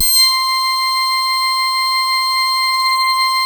BAND PASS.10.wav